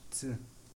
цы hats